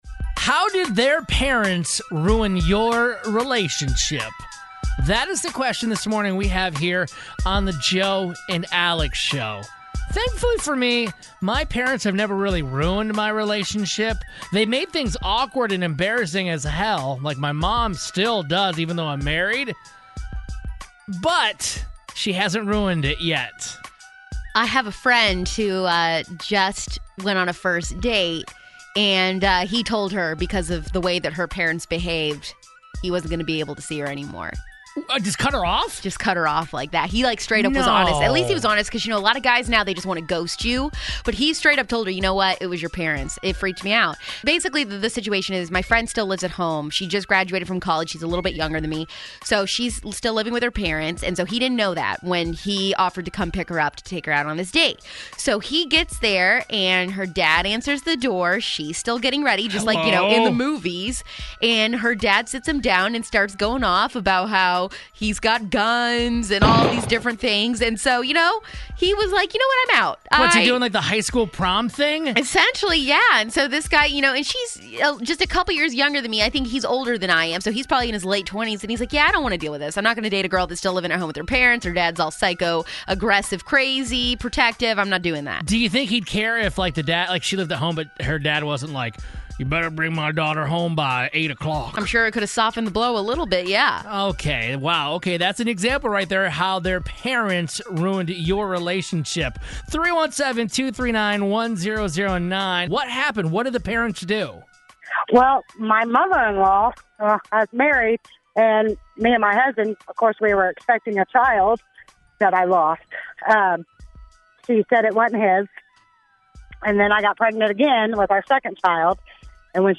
We had people call in to explain how their parents ruined your relationship or even stopped it from ever beginning.